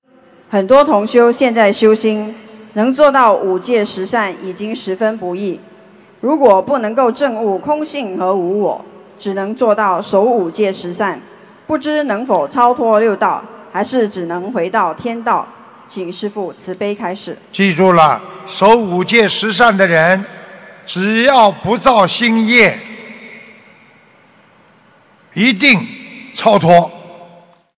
守五戒十善且不造新业，一定能超脱┃弟子提问 师父回答 - 2017 - 心如菩提 - Powered by Discuz!